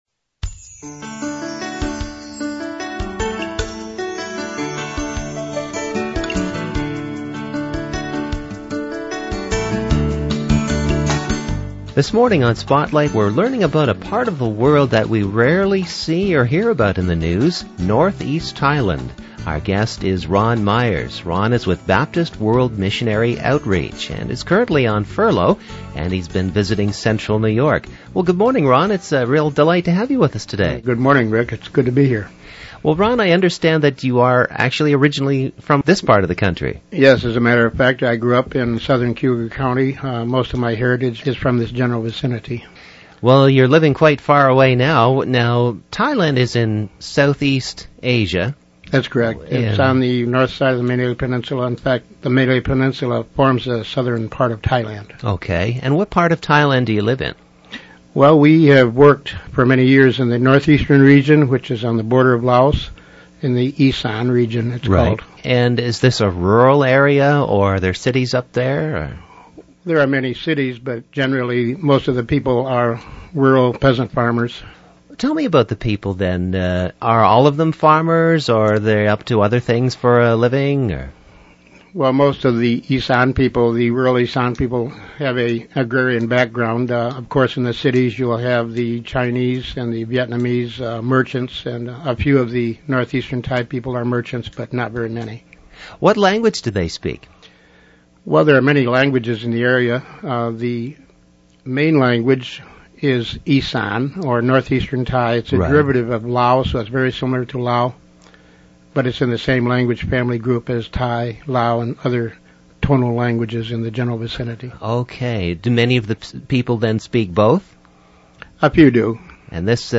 WMHR-Interview(2).mp3